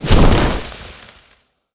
nature_spell_vines_blast_impact2.wav